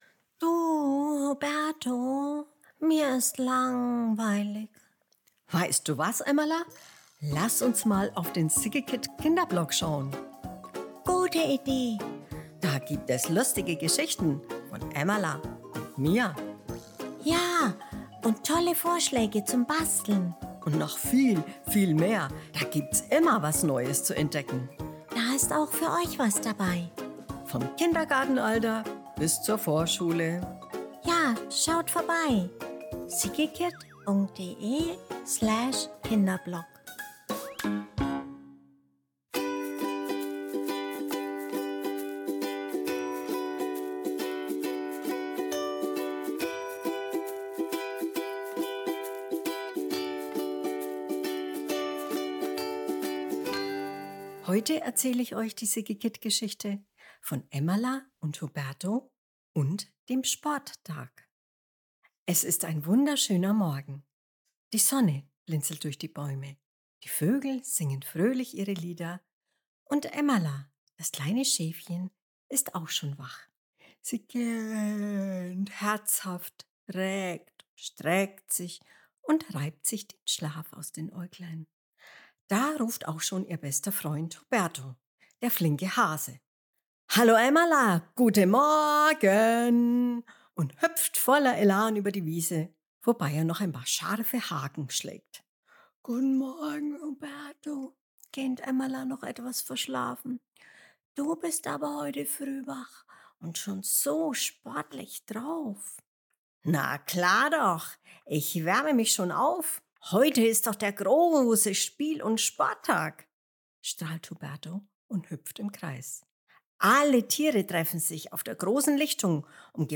Emmala, Huberto und der Sporttag August 19, 2025 Kinderblog Sommer, Vorlesegeschichten, Emmala & Huberto Ein sonniger Morgen im Wald: Emmala und Huberto freuen sich auf den großen Sporttag. Zusammen mit vielen Tieren erleben sie lustige Spiele – vom Tannenzapfen-Rollen bis zum Rückwärtshüpfen.